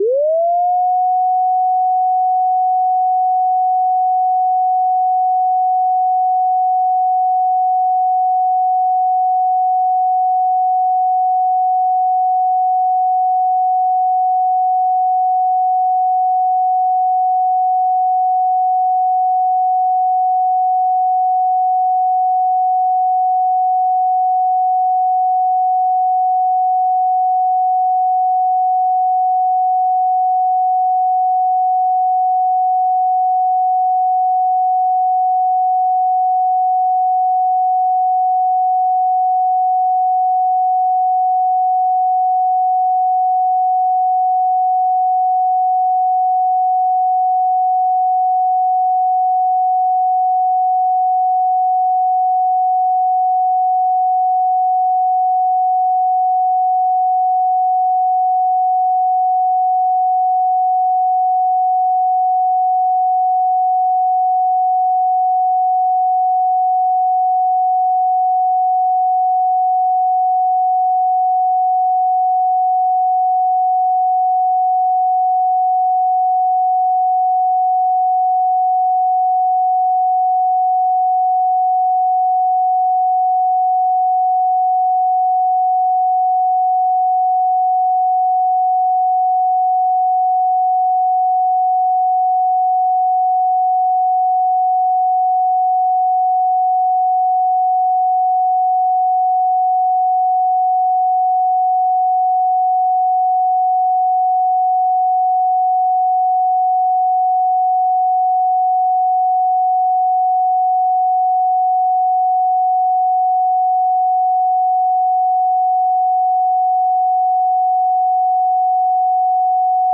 741 Hz Tone Sound Solfeggio Frequency
Solfeggio Frequencies